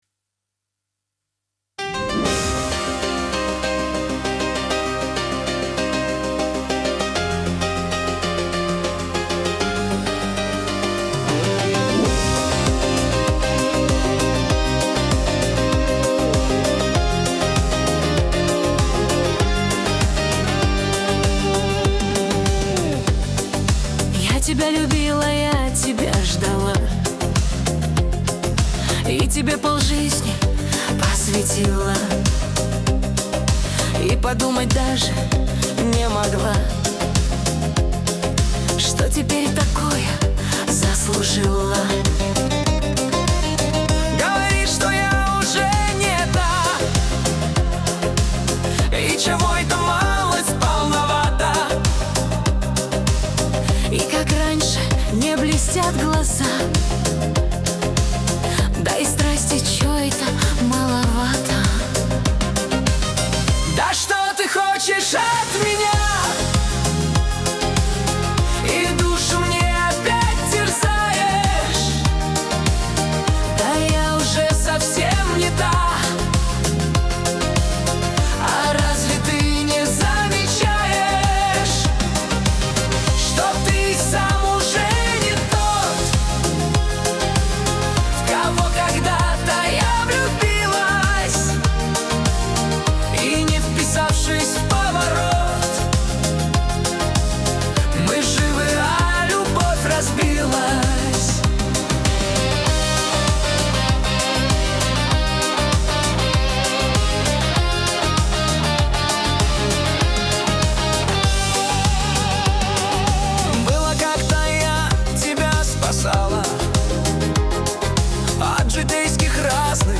Качество: 320 kbps, stereo
Шансон, Нейросеть Песни 2025